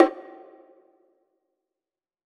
WBONGO HI.wav